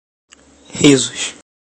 risos_3.mp3